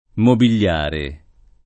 mobigliare [ mobil’l’ # re ]